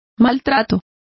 Complete with pronunciation of the translation of abuse.